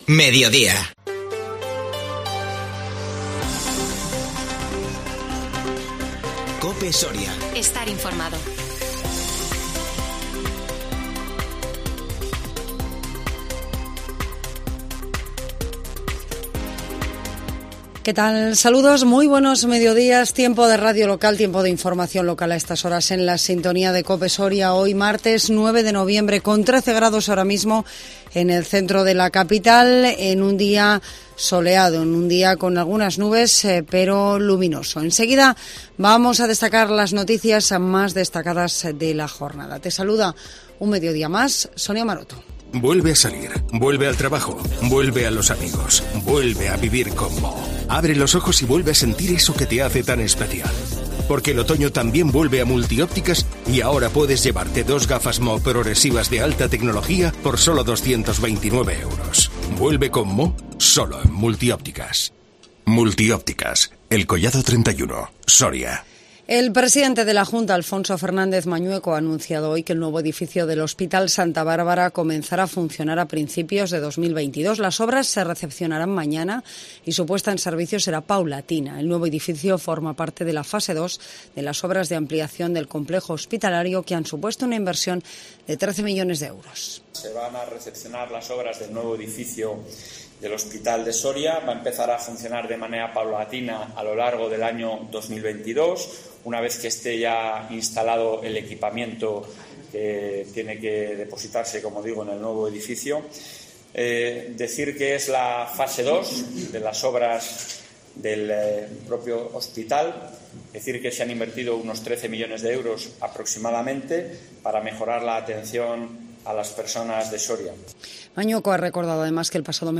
INFORMATIVO MEDIODIA 9 NOVIEMBRE 2021